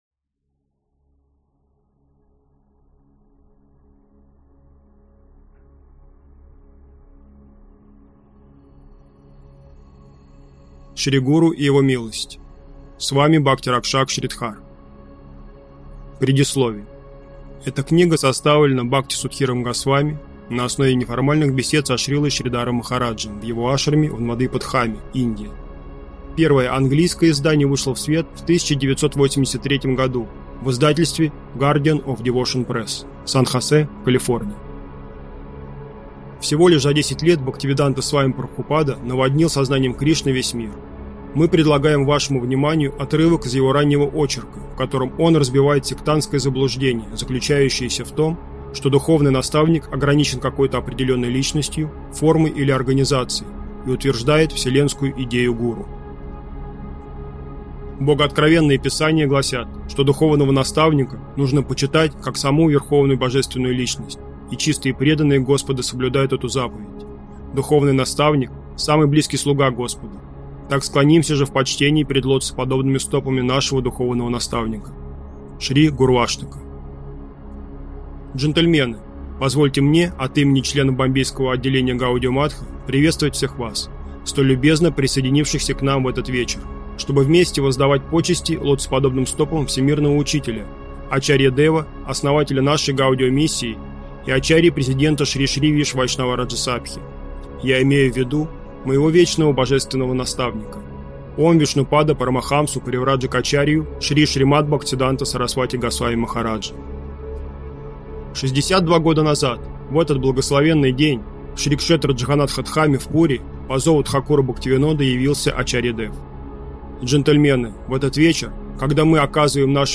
Аудиокнига Шри Гуру и Его Милость | Библиотека аудиокниг